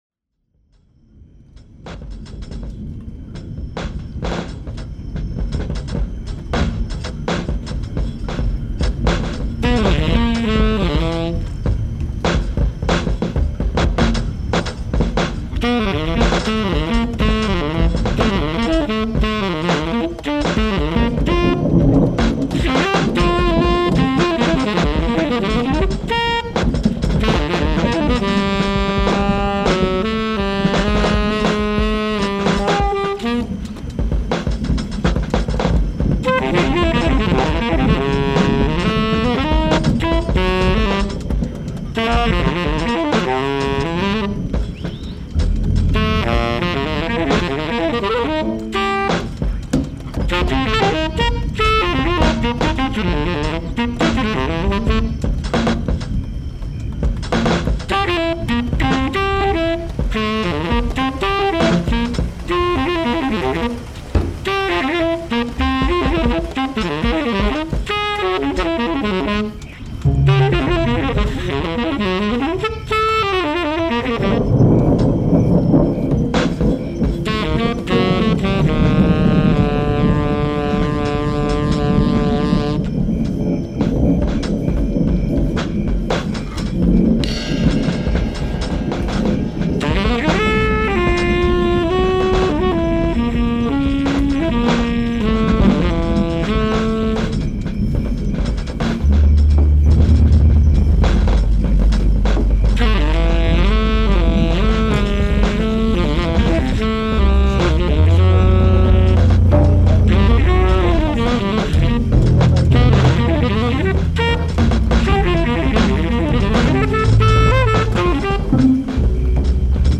Additional (live mashed) sonic fictions